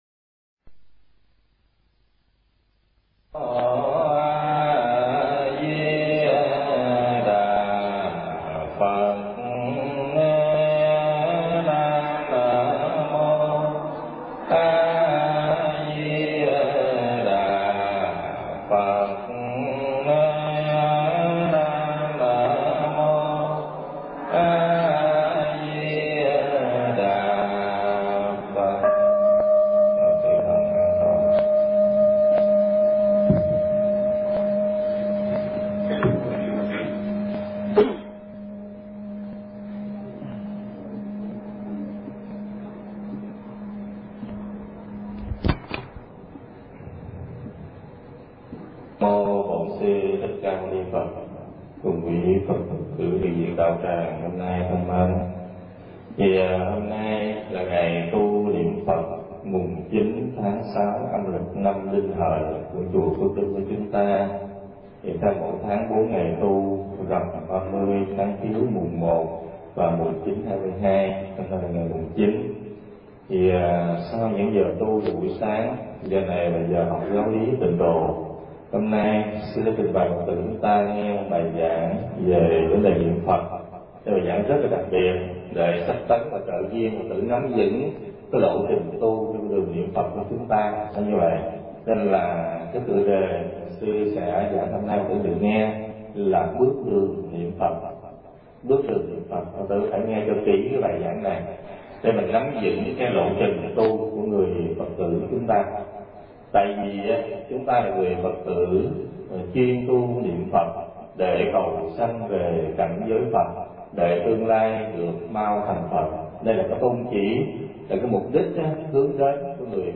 Tải mp3 Pháp Âm Bước Đường Niệm Phật